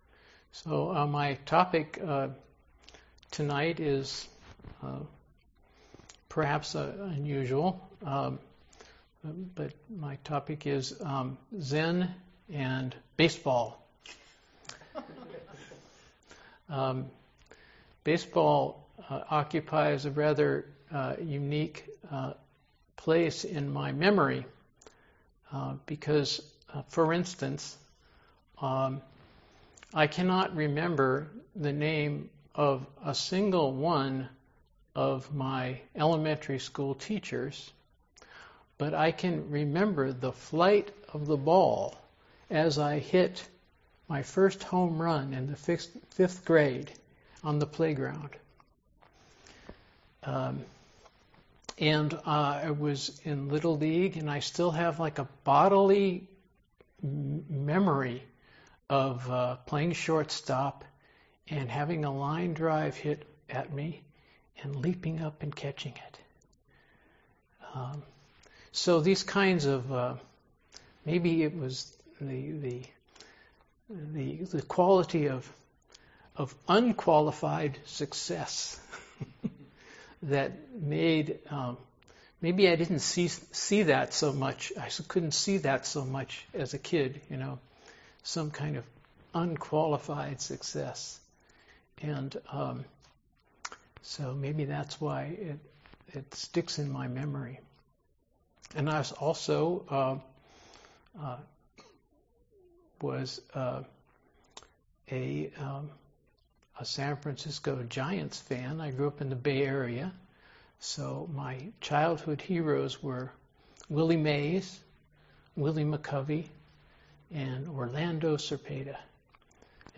Dharma Talk